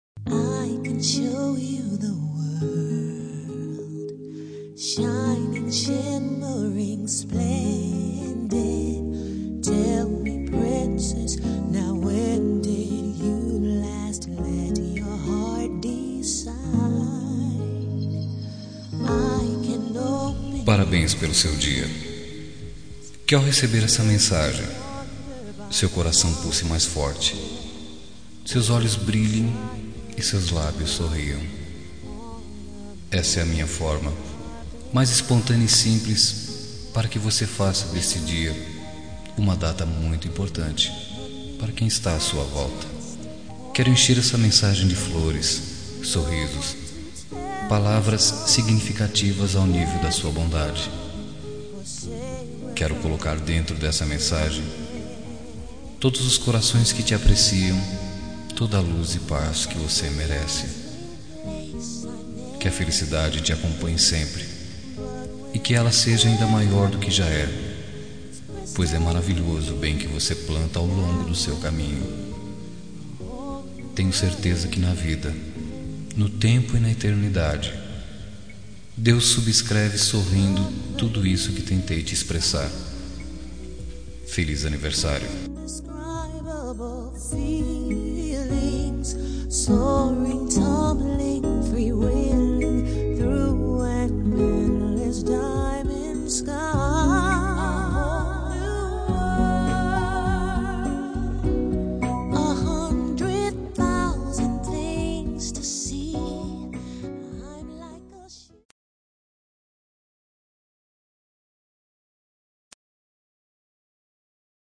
Telemensagem de Aniversário de Pessoa Especial – Voz Masculina – Cód: 1922